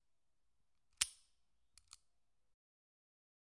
枪声 " 希瑟斯枪声效果2
描述：为音乐剧“海瑟斯”的高中制作录制了枪声音效。它是在Zoom H1上录制的，由戏剧帽枪制成。我将它录制在距离观众大约5英尺的位置，然后再远离观众大约40英尺的距离，并让声音在两次都反响。然后我复制了两个，并将两个原始声音混合在一起，加上每个声音向下倾斜1个八度音程和40英尺远的录音以2个八度音程向下，再加上较近的录音音高一个八度。然后，我们在Audacity中添加并调整了混响，并调整了要对齐的所有剪辑的同步，并调整了混音以使其尽可能真实。
标签： 枪弹 枪声 手枪 步枪 左轮手枪 帽子 38 枪击
声道立体声